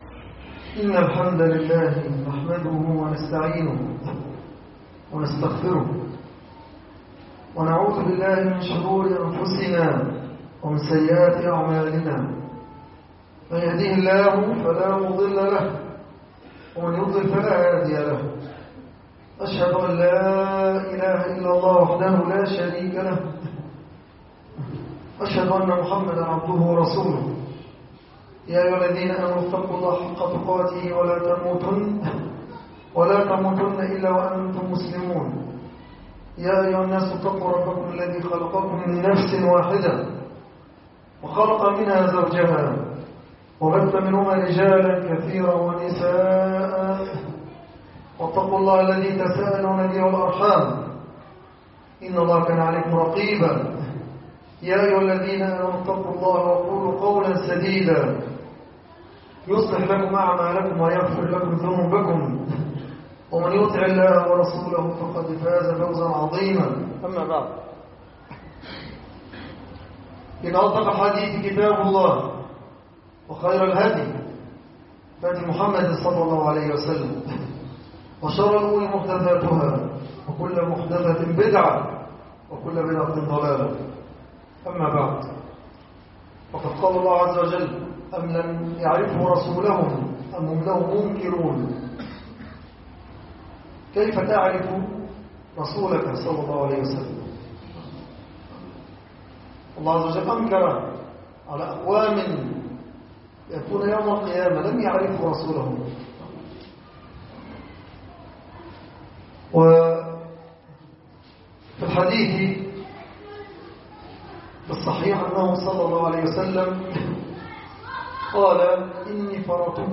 خطب الجمعة والأعياد